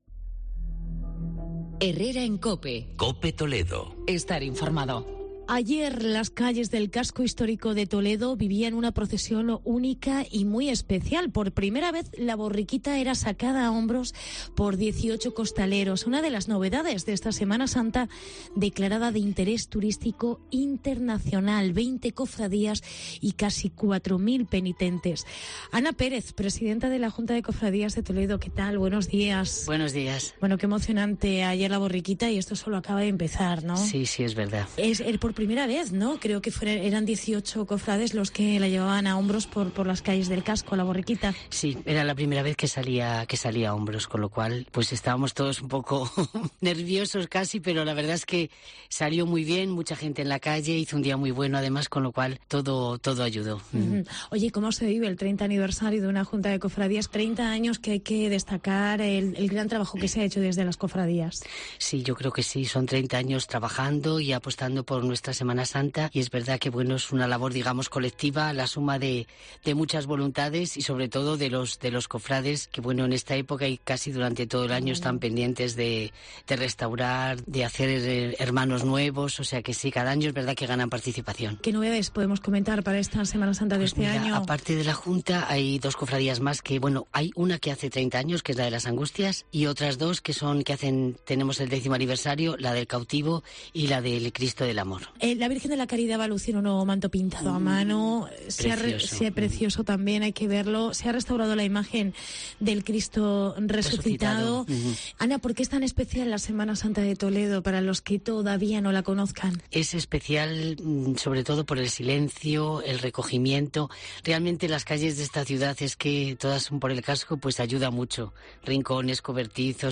Novedades de la Semana Santa en Toledo. Entrevista